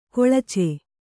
♪ koḷace